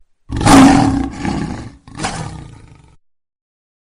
Lion Roar
Lion Roar is a free animals sound effect available for download in MP3 format.
080_lion_roar.mp3